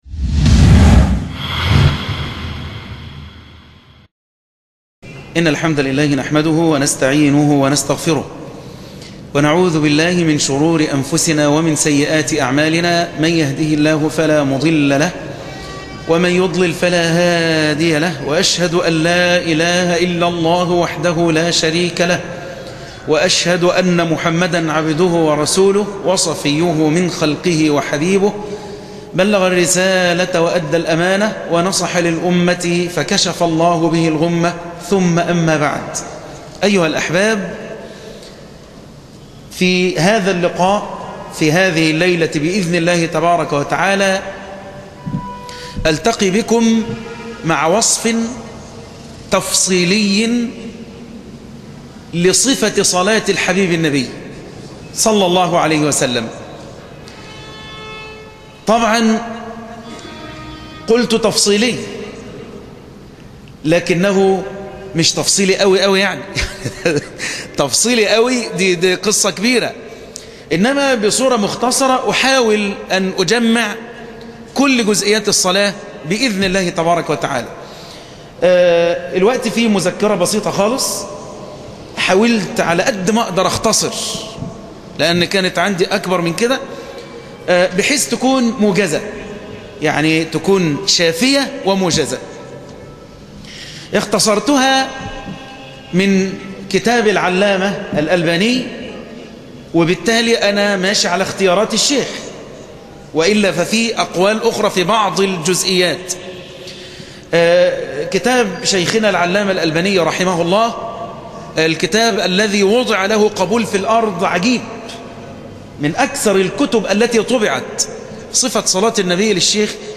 المحاضرة الثالثة ( صفة صلاة النبي صلى الله عليه وسلم )